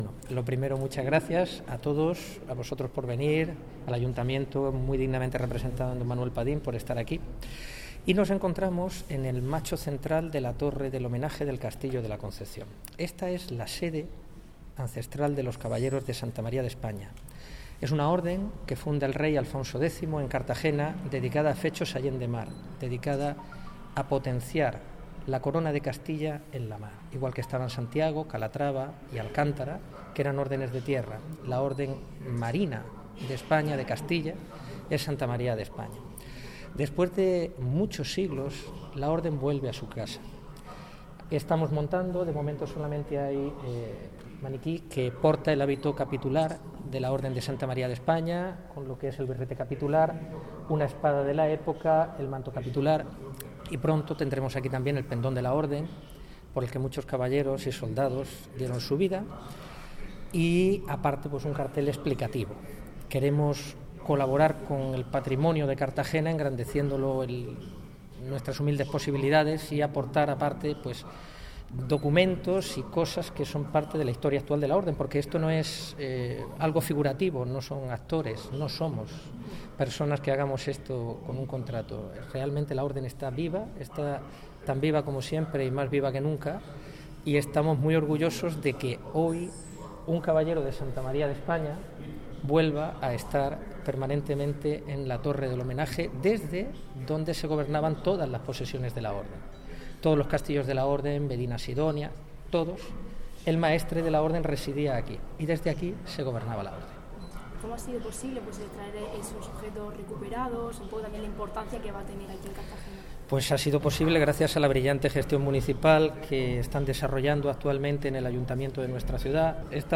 El viernes 15 de noviembre tuvo lugar el acto en la Sala de Recepciones del Palacio Consistorial
La Orden de Santa María de España hizo entrega este viernes 15 de noviembre de la Gran Cruz de Honor al Ayuntamiento de Cartagena, acto que se celebró en la Sala de Recepciones del Palacio Consistorial.